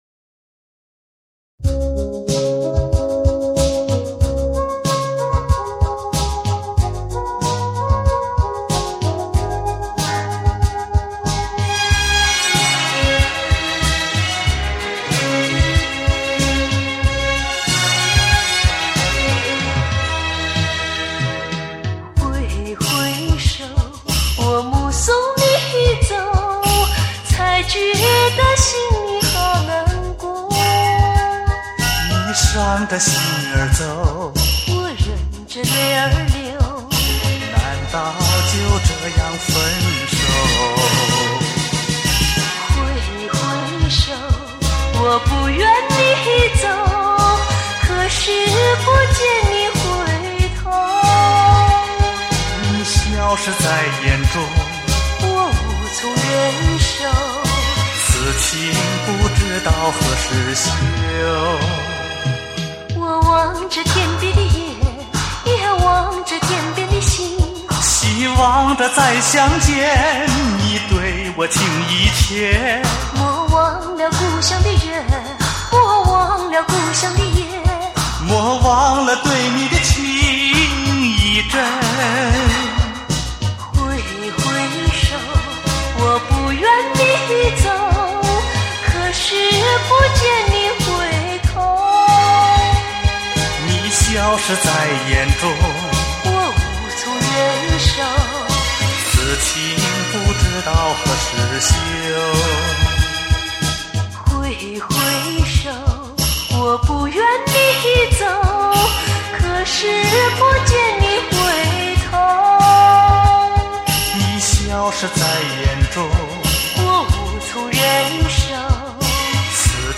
台湾最新情歌